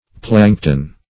Plankton \Plank"ton\ (pl[a^][ng]k"t[o^]n), n. [NL., fr. Gr.